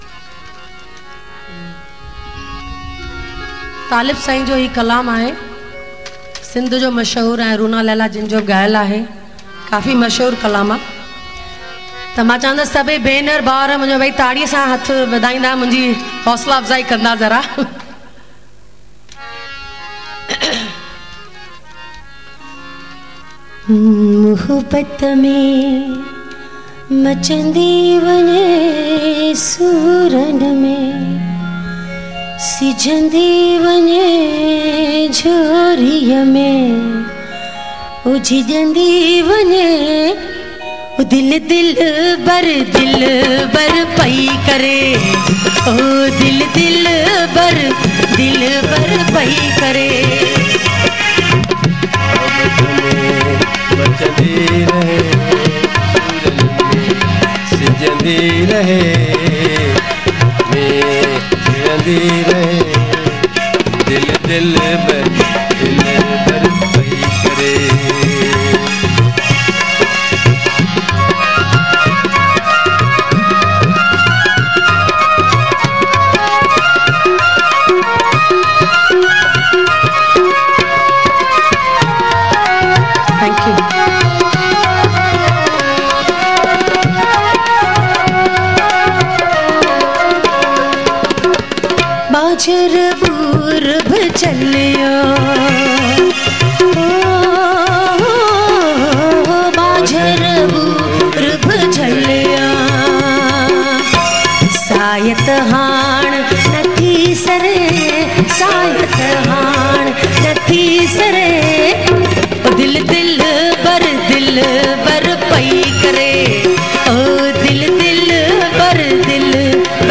Format: LIVE
Live Performance